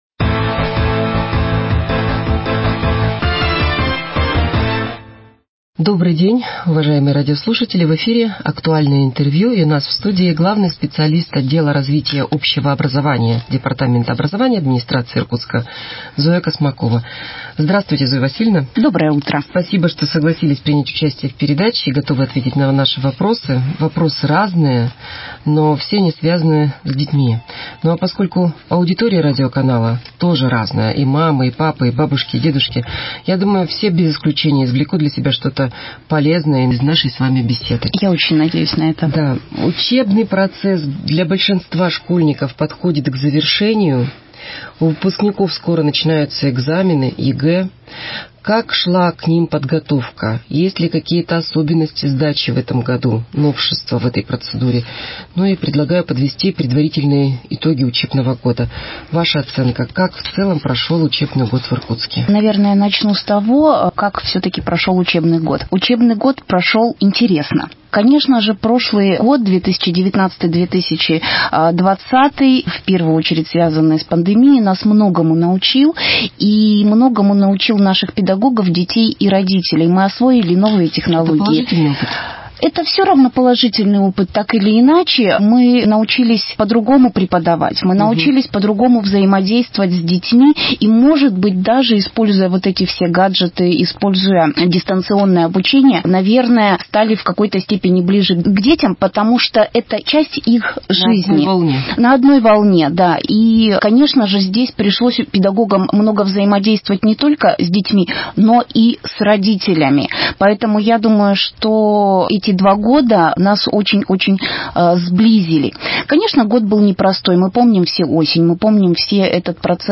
Актуальное интервью: Завершение учебного года в Иркутске 20.05.2021